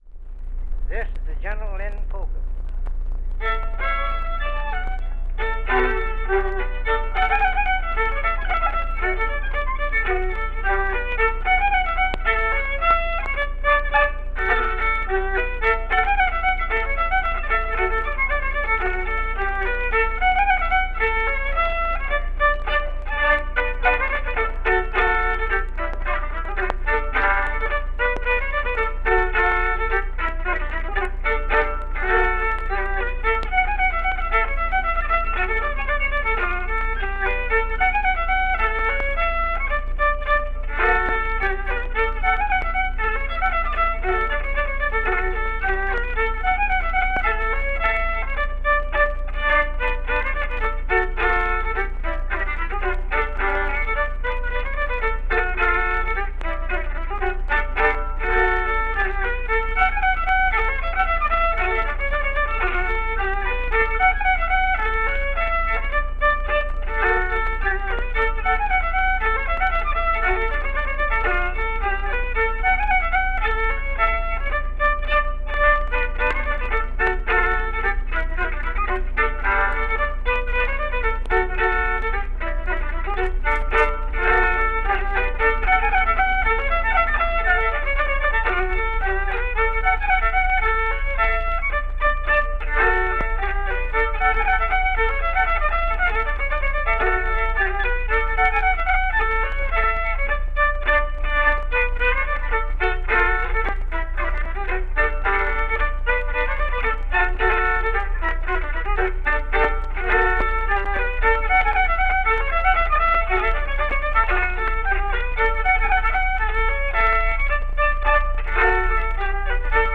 California Gold: Northern California Folk Music from the Thirties.